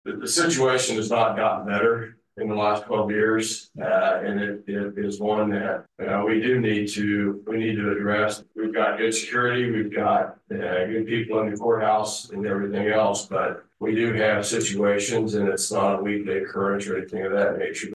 County Attorney Barry Wilkerson spoke at Thursday’s county commission meeting.